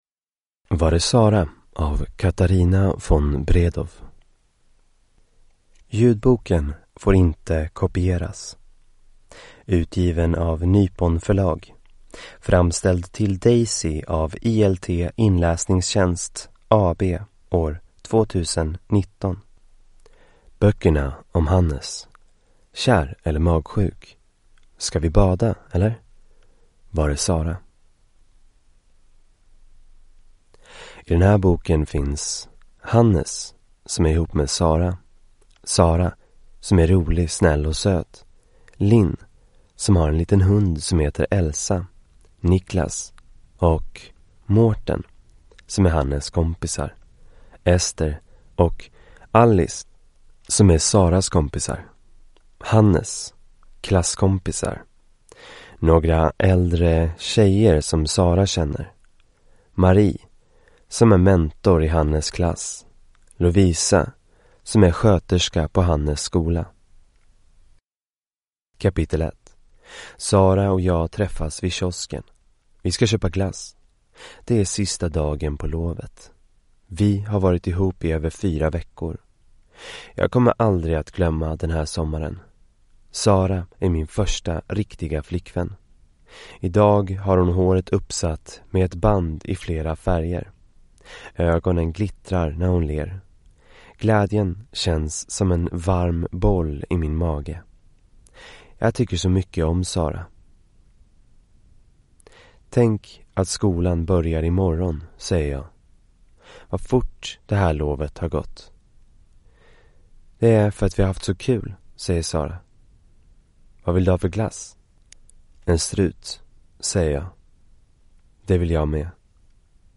Var är Sara? (ljudbok) av Katarina von Bredow | Bokon